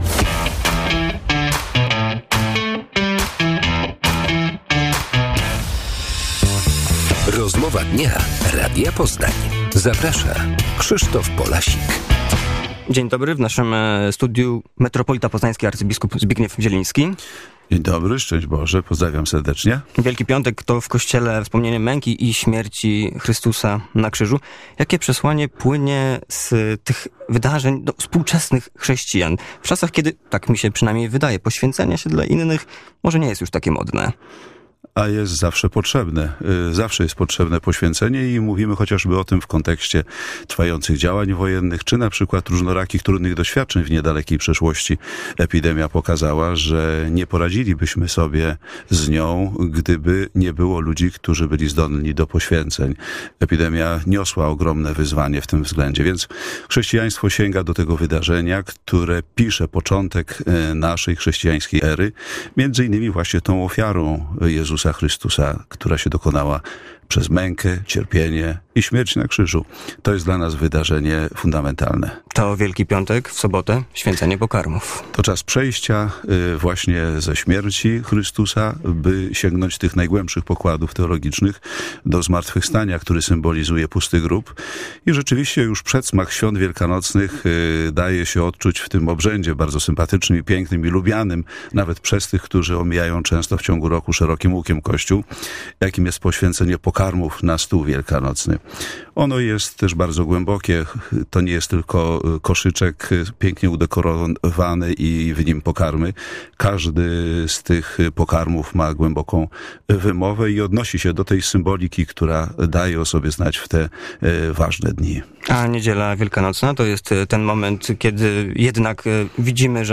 Rozmowa Dnia - abp Zbigniew Zieliński